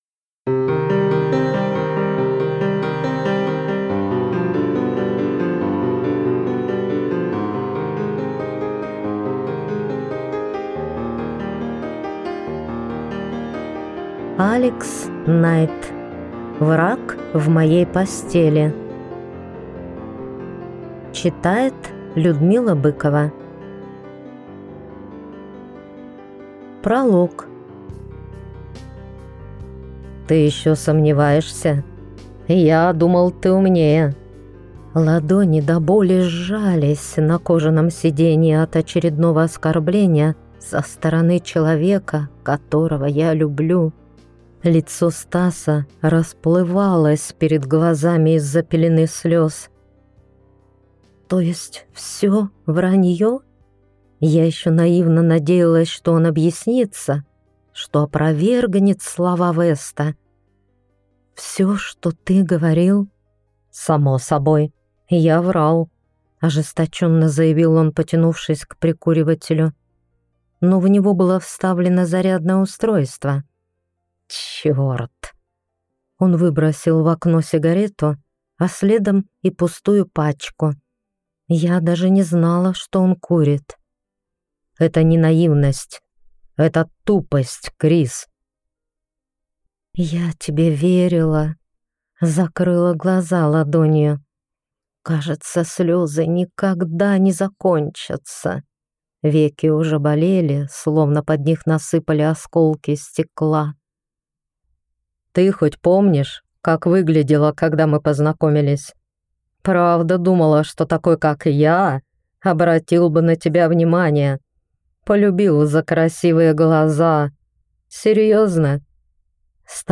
Аудиокнига Враг в моей постели | Библиотека аудиокниг